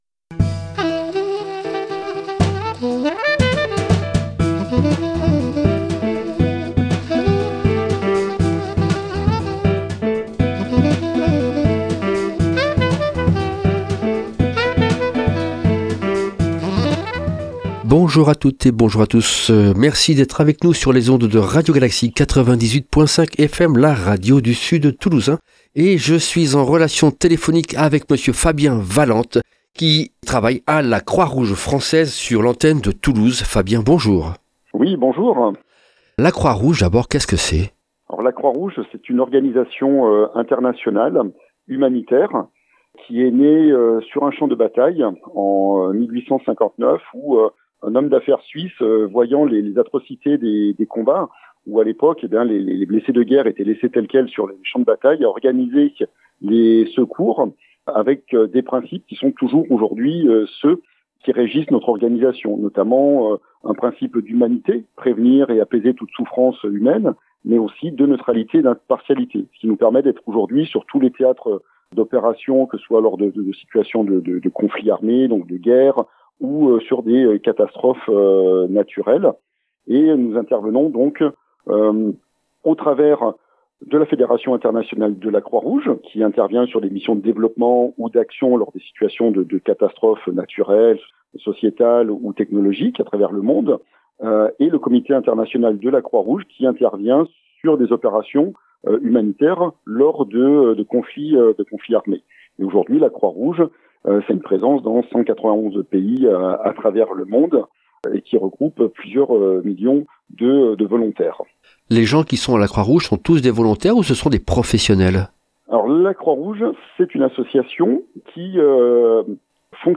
Genre : Inteview.